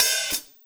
Cymbol Shard 13.wav